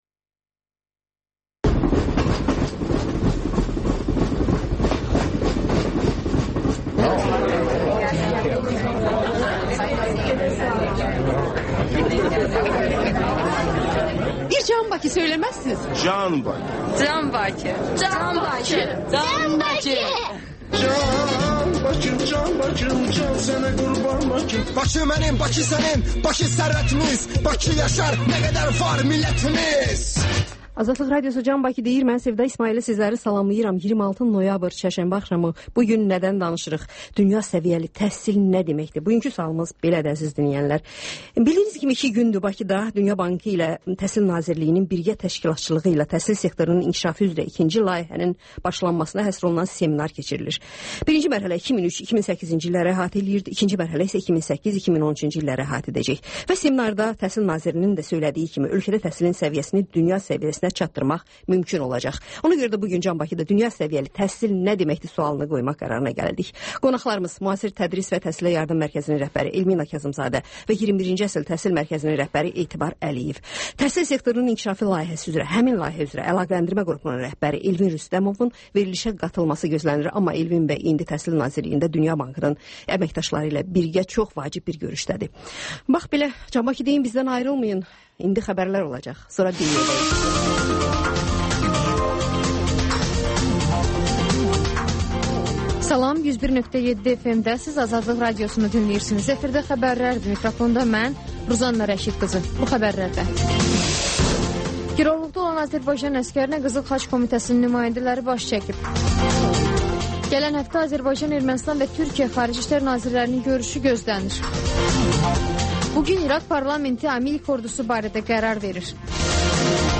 Xəbərlər, sonra CAN BAKI verilişi: Bakının ictimai və mədəni yaşamı, düşüncə və əyləncə həyatı… (Təkrarı saat 14:00-da)